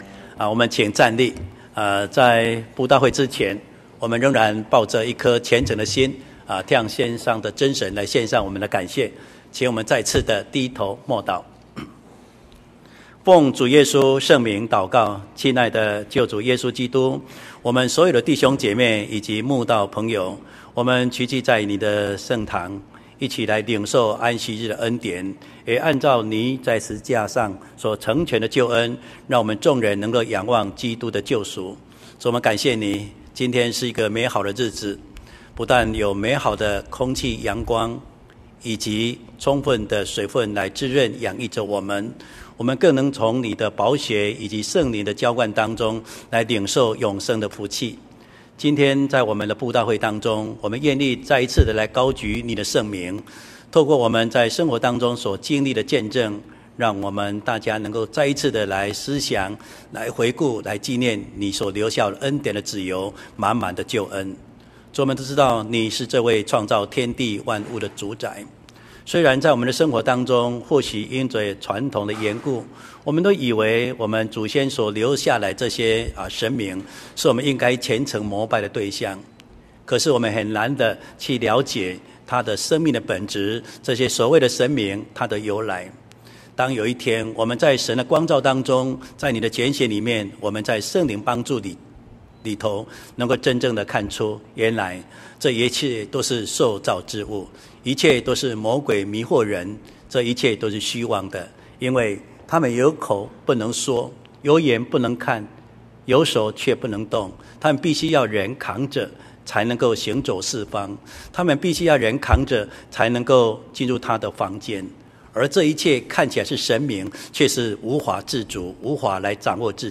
月佈道聚會：神使我安然居住-講道錄音